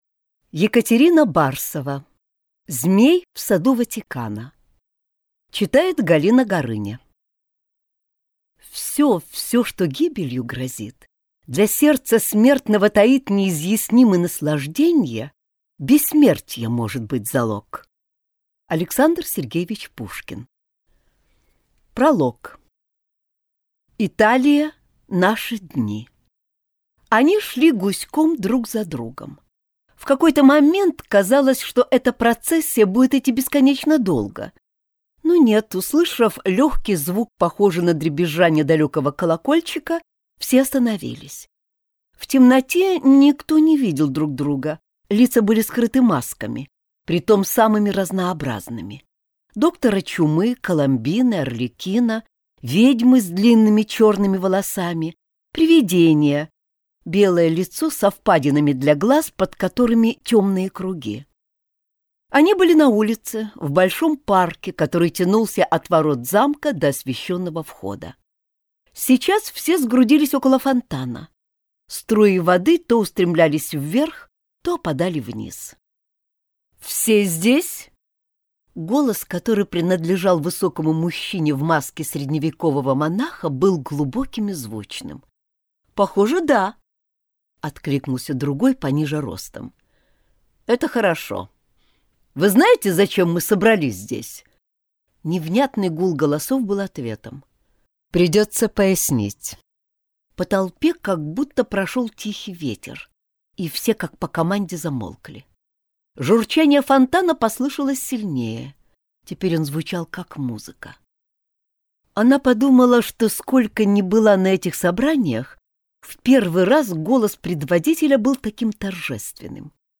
Аудиокнига Змей в саду Ватикана | Библиотека аудиокниг
Прослушать и бесплатно скачать фрагмент аудиокниги